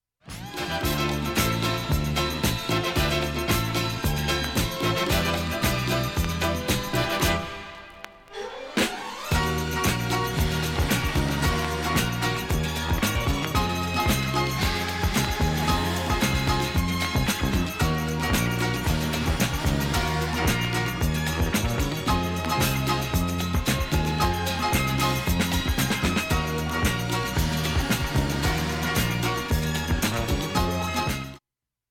SOUL、FUNK、JAZZのオリジナルアナログ盤専門店
盤面きれいで音質良好全曲試聴済み。 瑕疵部分 A-1中盤にかすかなプツが ２回と３回と２回出ます。